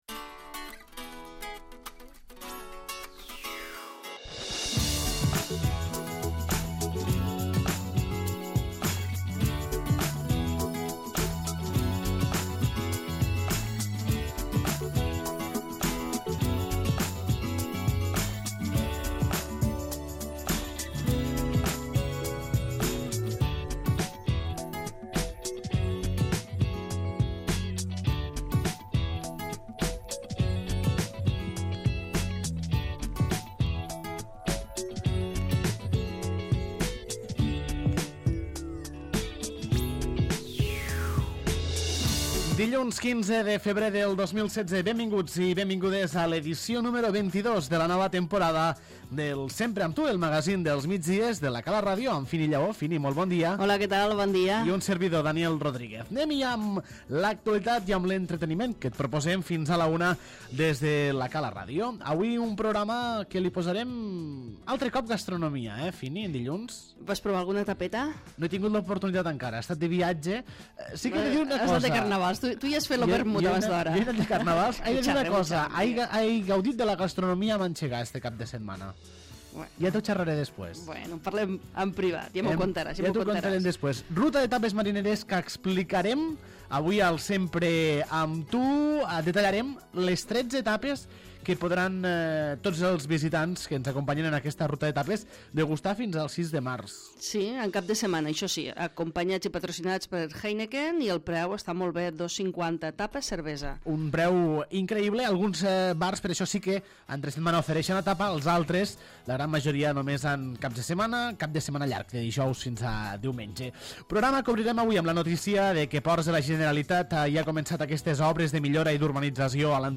22è programa del magazín dels migdies de La Cala Ràdio, el Sempre amb tu, corresponent a dilluns 15 de febrer de 2016.